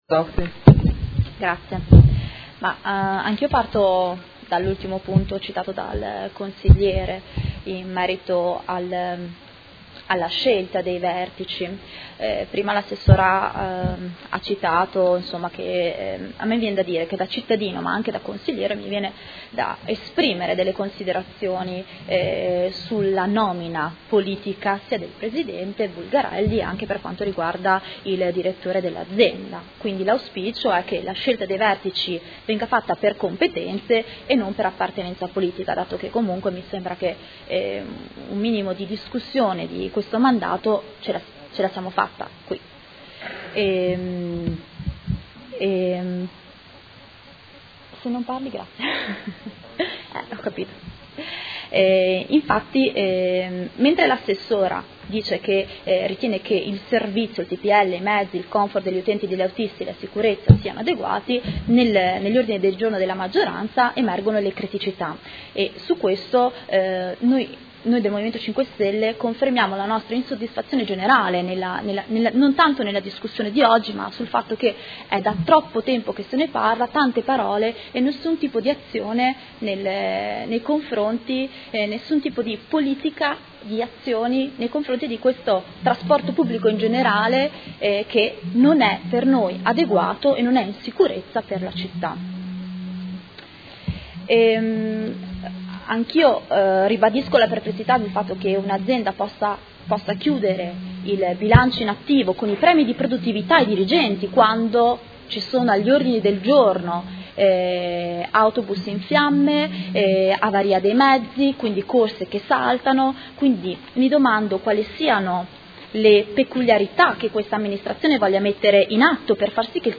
Elisabetta Scardozzi — Sito Audio Consiglio Comunale
Seduta del 21/06/2018 Dibattito. Delibera nr. 84798 Convenzione tra i Soci pubblici modenesi di SETA S.p.A. - Approvazione